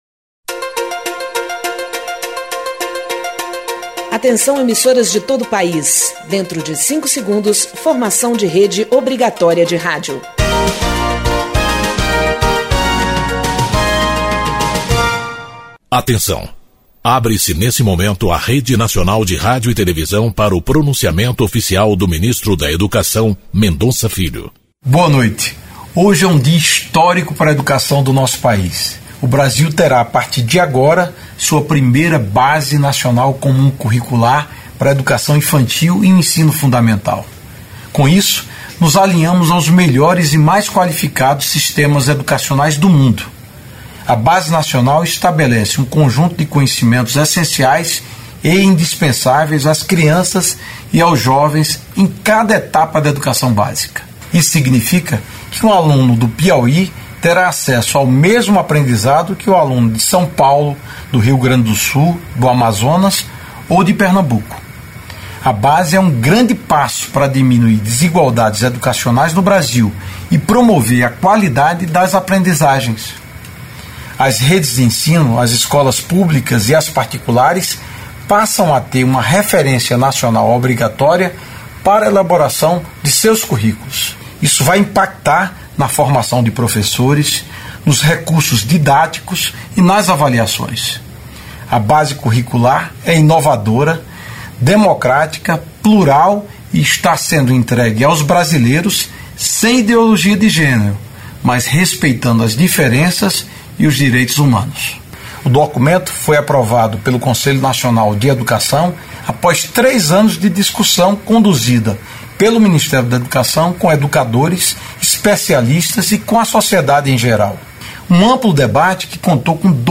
Pronunciamento do Ministro da Educação Mendonça Filho